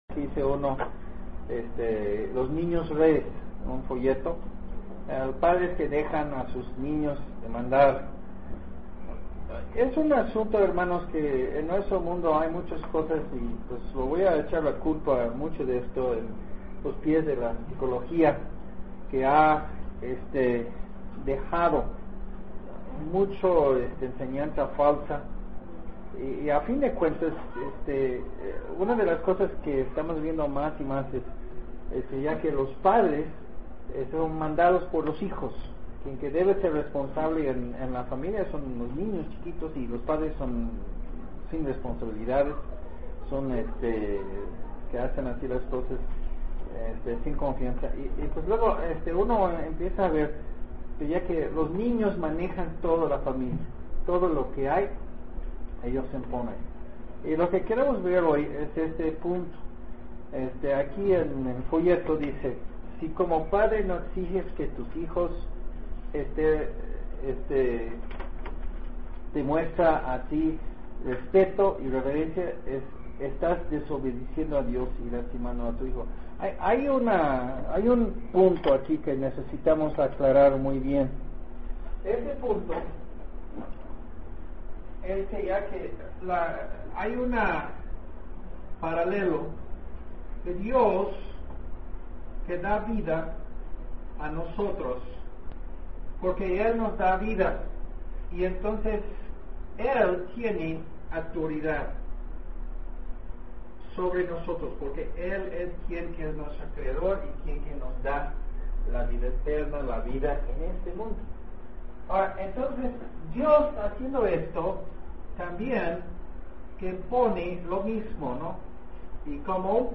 fam21 Los Niños Reyes Sermon en Audio